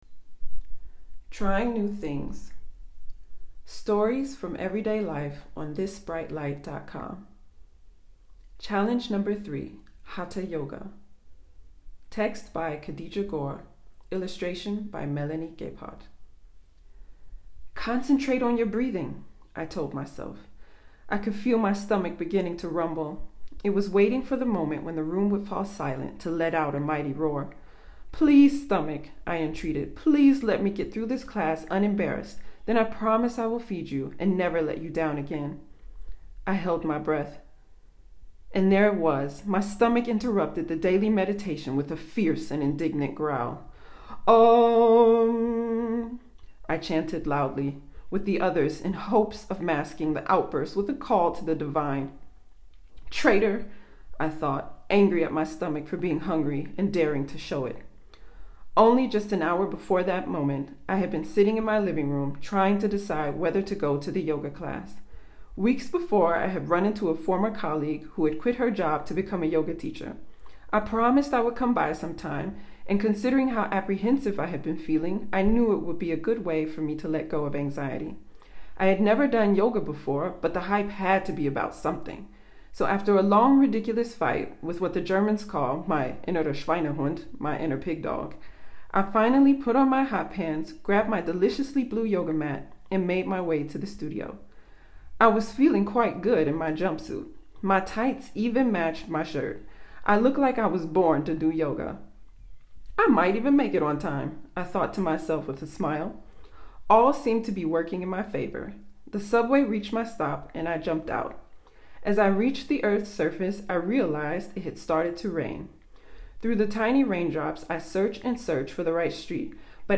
Enjoy the text below or listen to me read the story here: